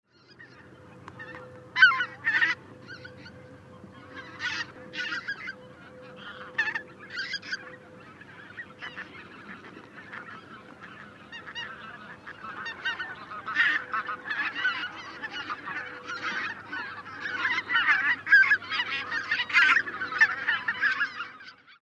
White-fronted Goose